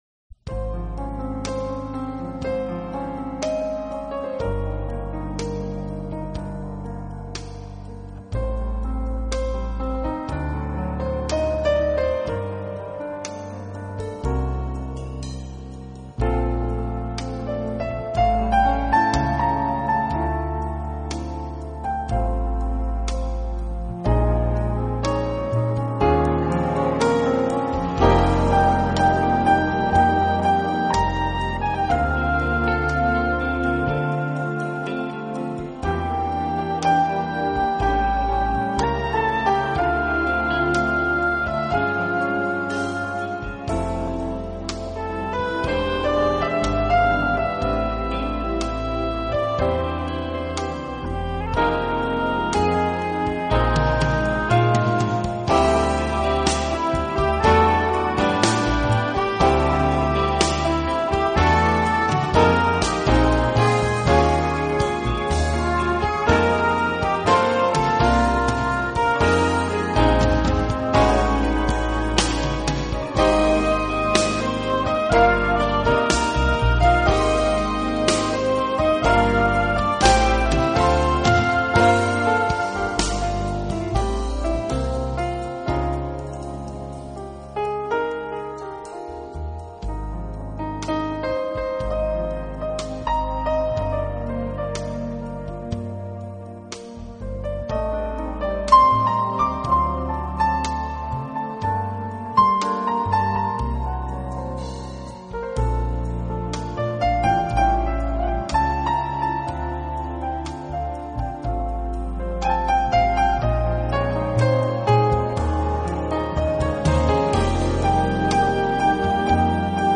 音乐风格: 爵士